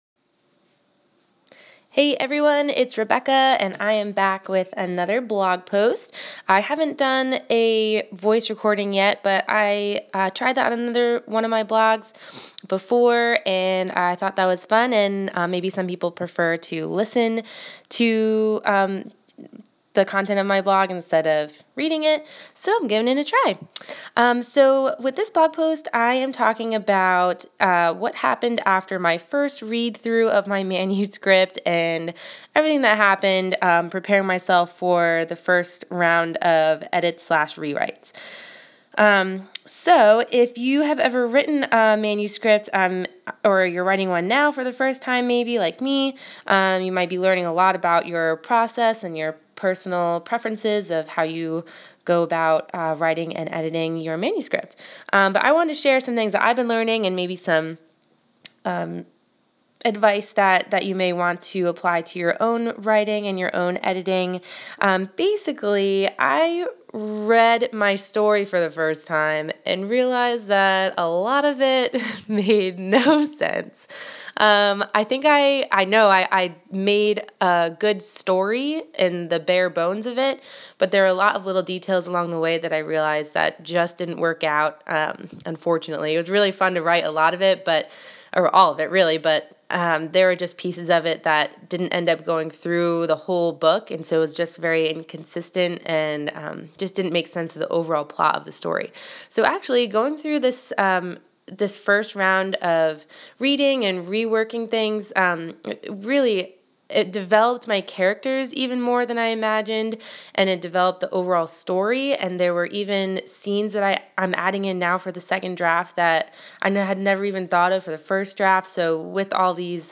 I created a voice recording for this blog post just for you!